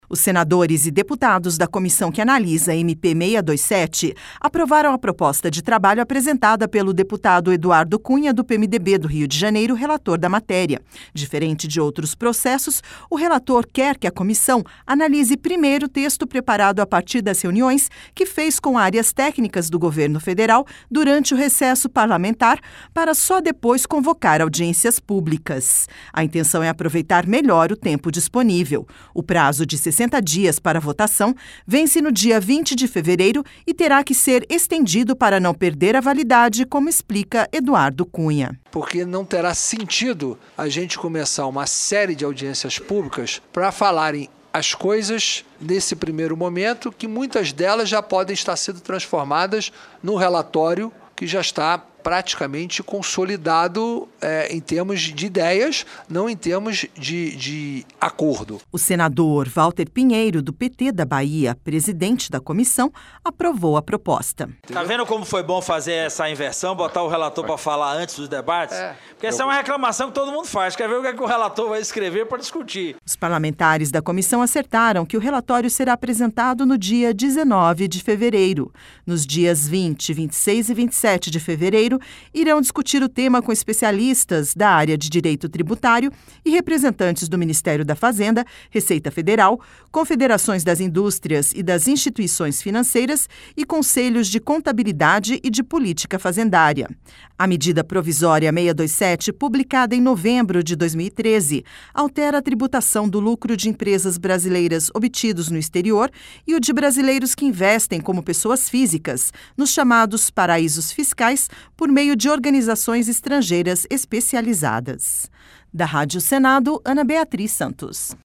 RadioAgência
(REPÓRTER) O senador Walter Pinheiro, do PT da Bahia, presidente da comissão, apoiou a proposta.